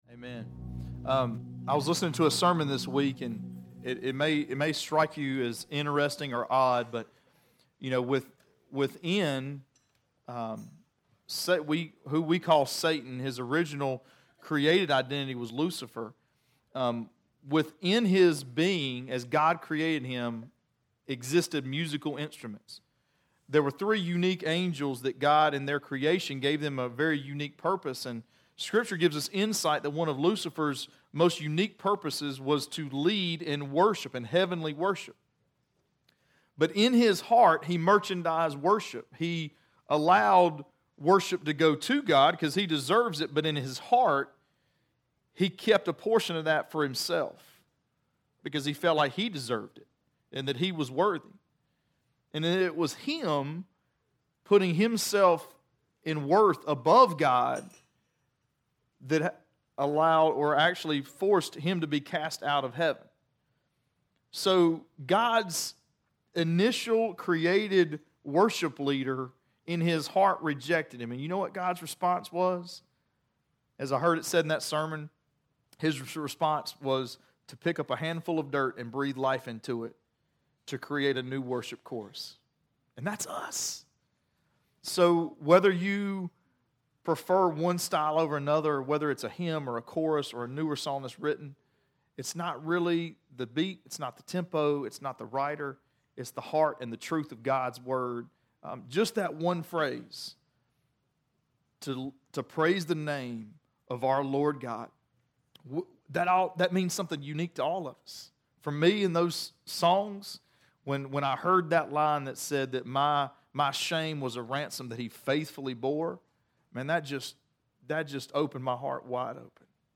Sermon-9_9_18-1.mp3